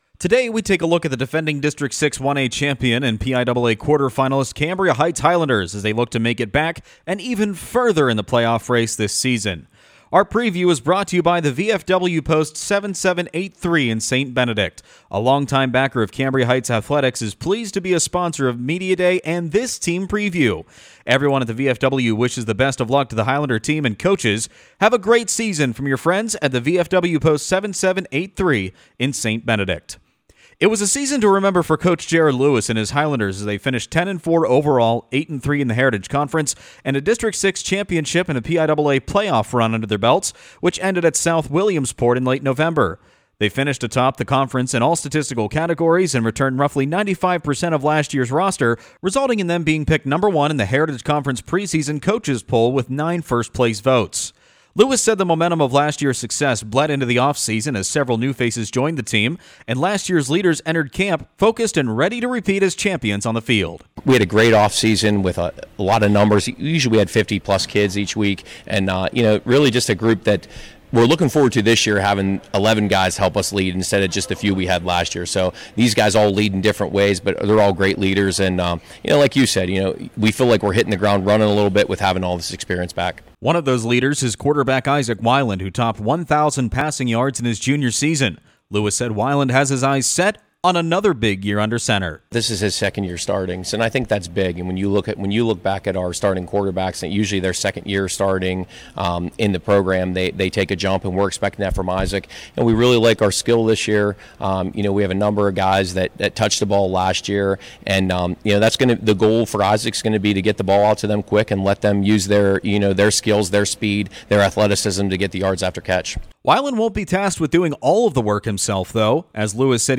Our latest high school football preview details the Cambria Heights Highlanders, who come off a 2023 season that saw them win the District 6 championship and make an appearance in the PIAA state playoffs.